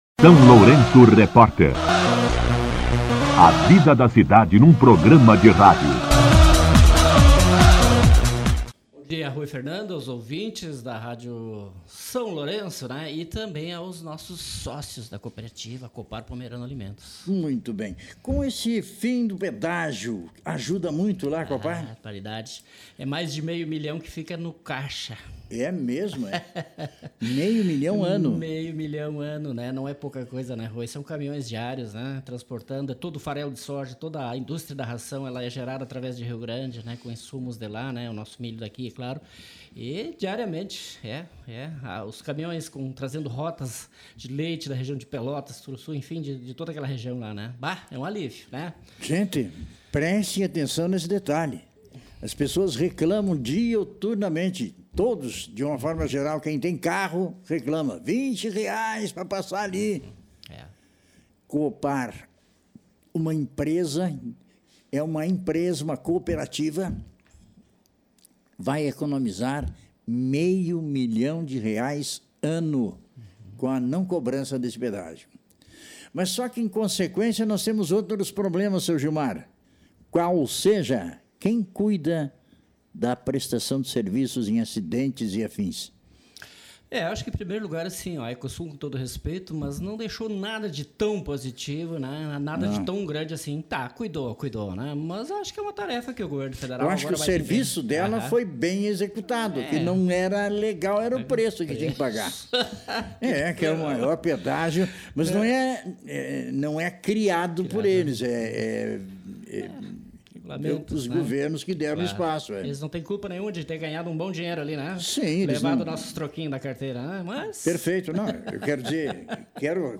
entrevista-04.03-coopar.mp3.mp3